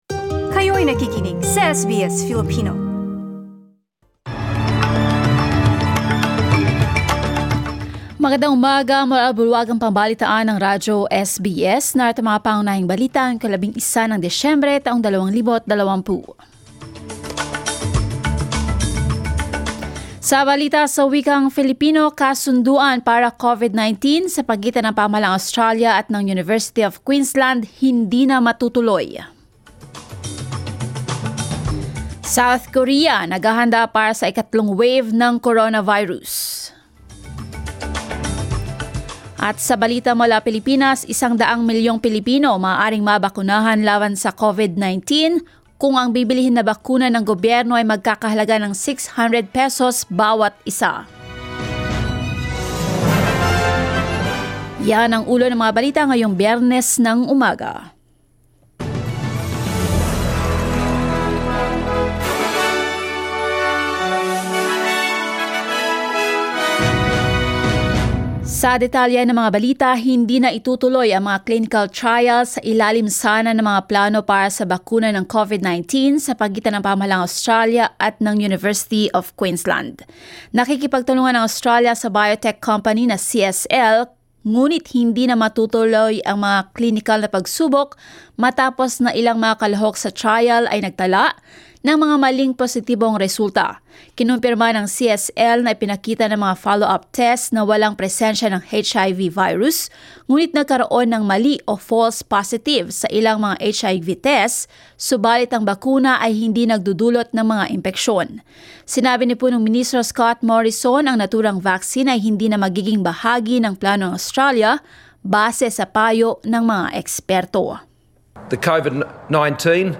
SBS News in Filipino, Friday 11 December